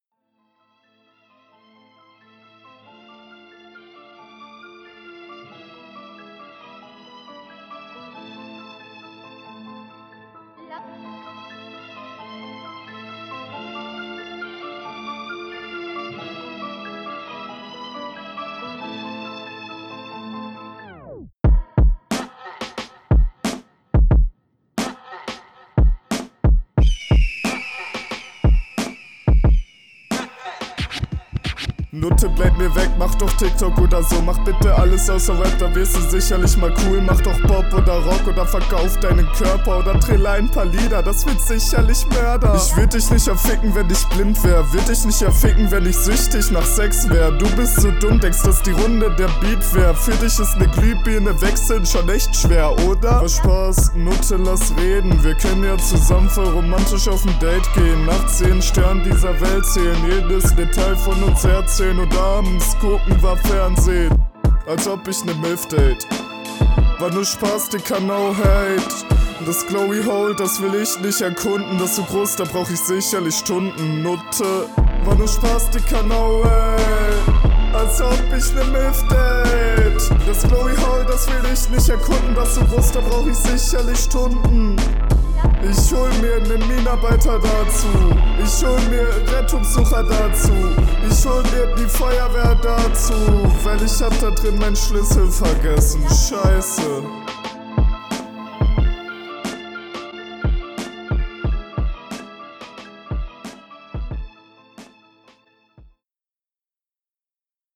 Beatwahl ist ganz nice hat was. Dein Einstieg kommt etwas unkonrolliert.